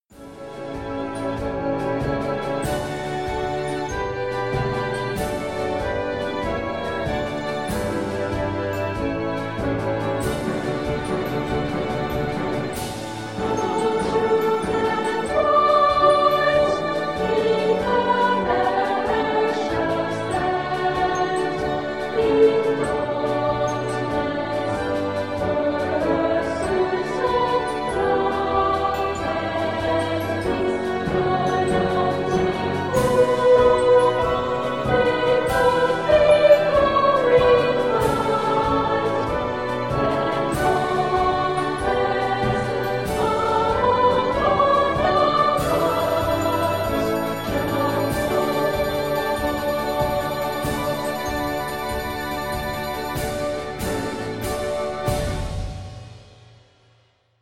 Orchestra with Vocals